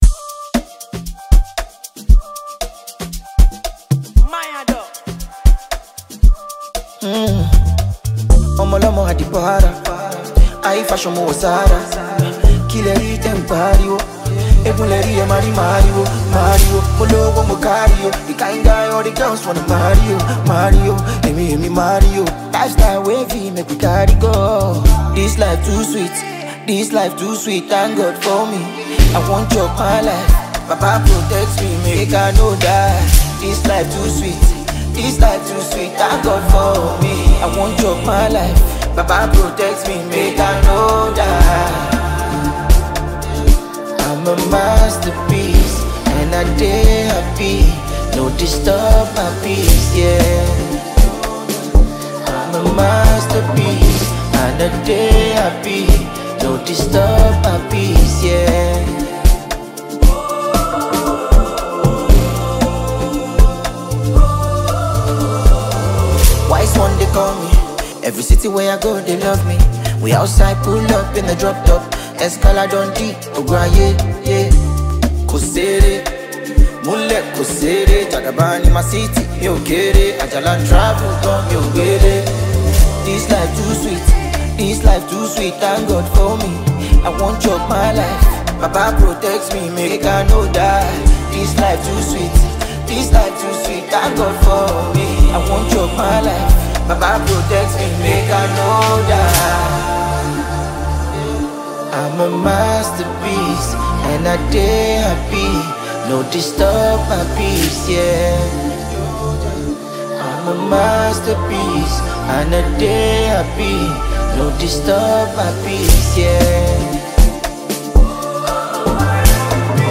With his signature style and catchy melodies
sweet rhythm, smooth vocals, and captivating lyrics